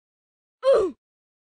Play, download and share oof soul original sound button!!!!
oof_D9Sv174.mp3